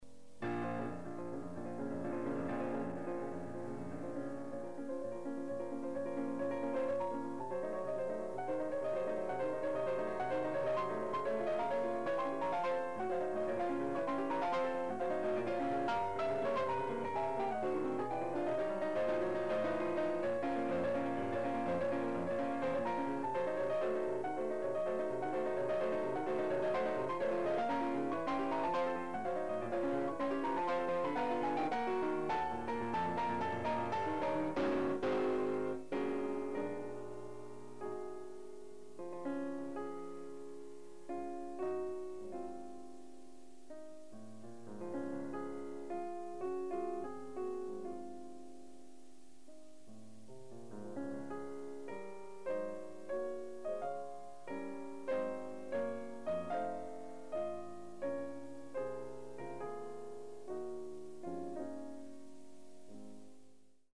Soloist
Recorded September 18, 1973 in the Ed Landreth Hall, Texas Christian University, Fort Worth, Texas
Sonatas (Piano)
performed music